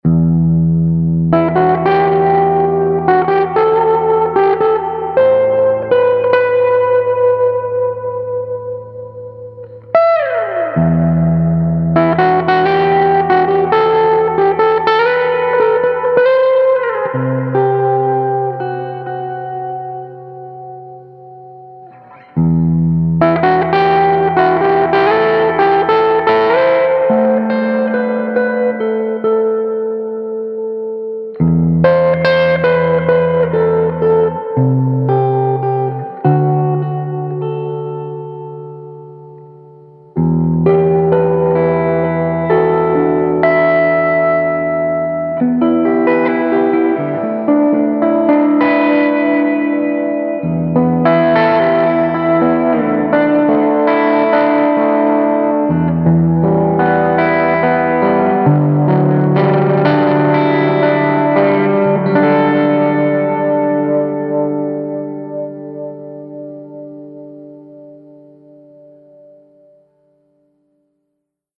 5W Class A - Single-Ended - 6V6 or 6L6 - Tube Rectified ~ ALL NEW Triode REVERB ~ 14lbs
The Fog • Gibson 335 • Heavy Reverb   1:11